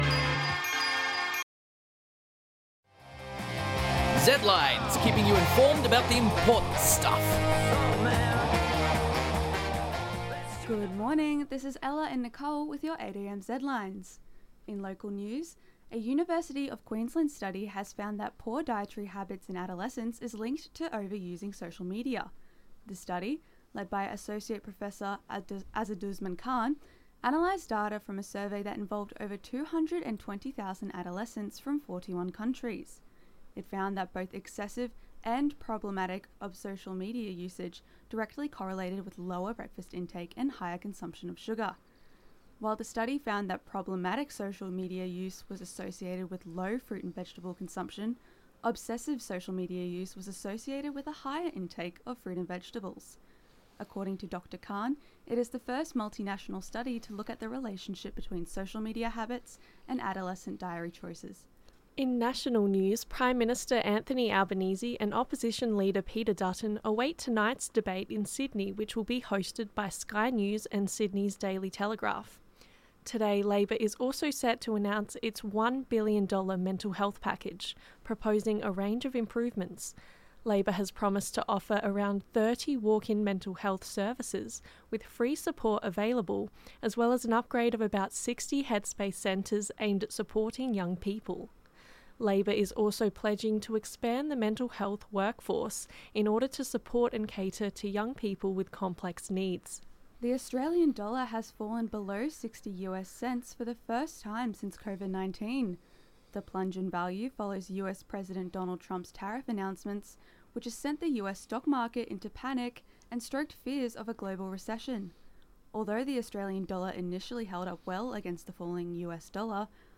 Stock Exchange Board (Pixabay/Pexels under CC BY-ND 2.0) Zedlines Bulletin 8am zedlines 8.04.25.mp3 (3.1 MB)